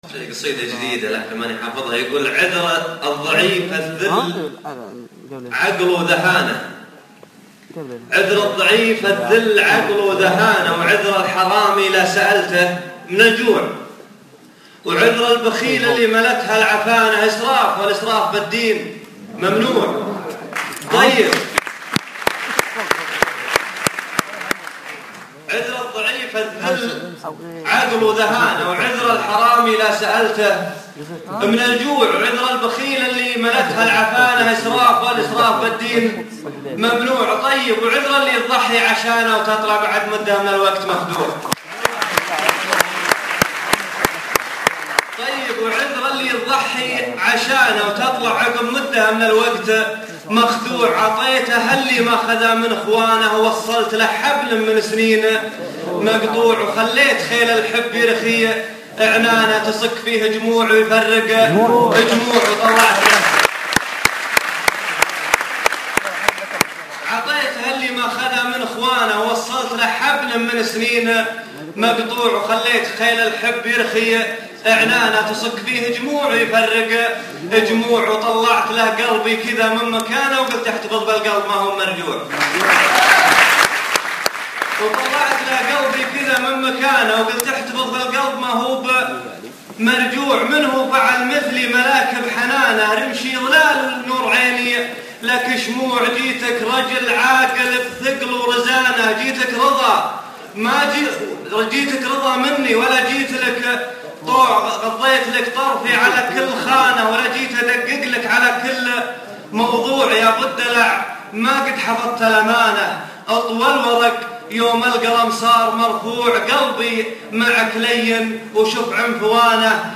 عذر الضعيف [ امسيه كتارا ]